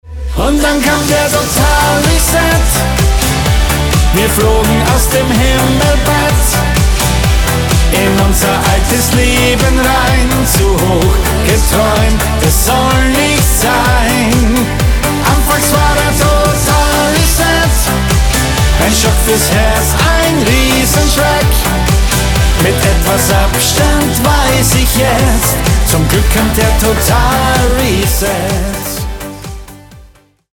der österreichische Schlagersänger
Emotional, ehrlich und musikalisch am Puls der Zeit.
Der Titel ist tanzbar, gefühlvoll und absolut zeitgemäß.